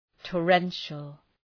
Προφορά
{tɔ:’renʃəl}